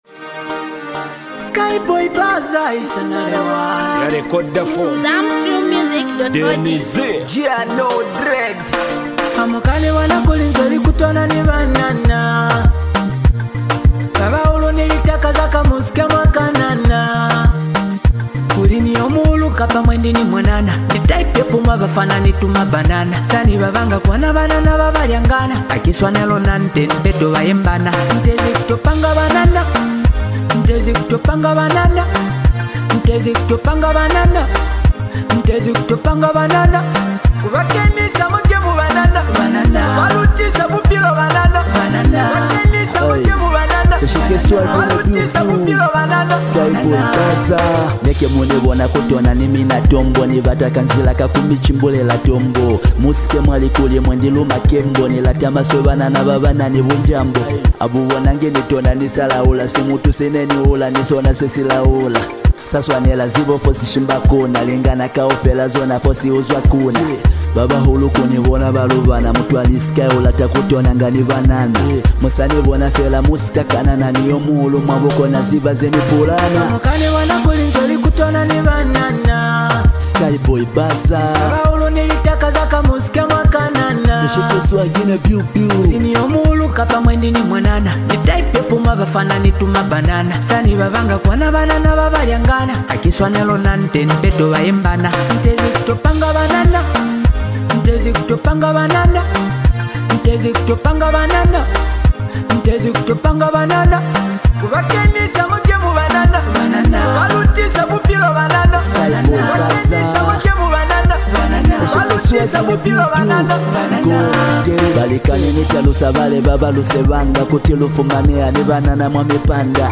catchy rhythm and charismatic delivery
confident flow and magnetic presence
a beat that is bold, infectious, and expertly crafted